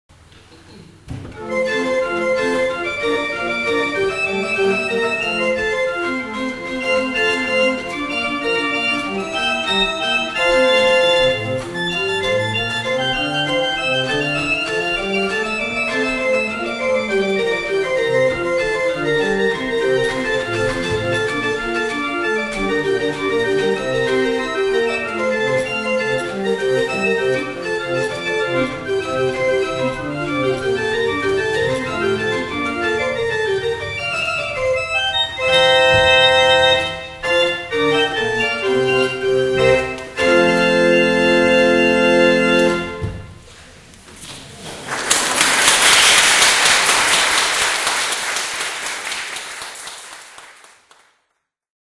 Old Norriton Presbyterian Church - Norristown, PA
From a recital played on July 19, 1998.
The sound and general construction of the instrument is much more like an organ from the mid eighteenth century than the mid nineteenth century. The sound is very bright and intense but without any hint of harshness.